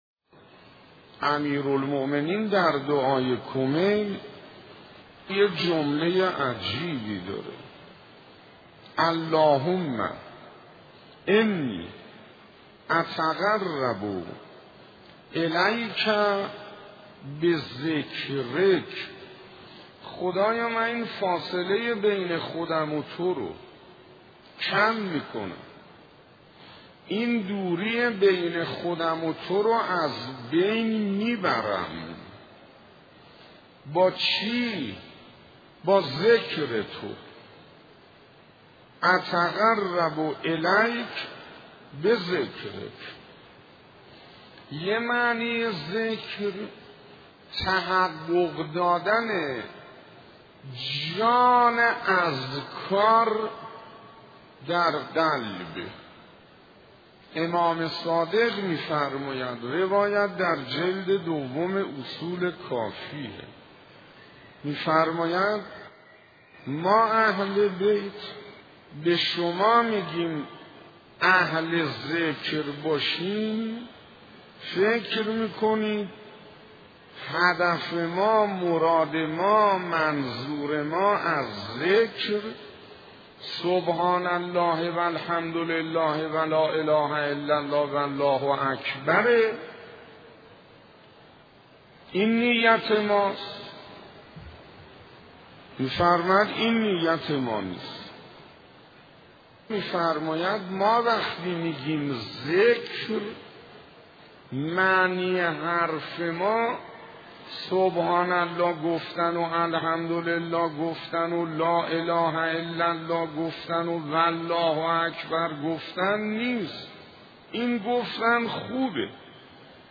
مجموعه پادکست «روایت بندگی» با کلام اساتید بنام اخلاق به کوشش ایکنا گردآوری و تهیه شده است که پنجاه ودومین قسمت این مجموعه با کلام شیخ حسین انصاریان با عنوان «حقیقت ذکر» تقدیم مخاطبان گرامی ایکنا می‌شود.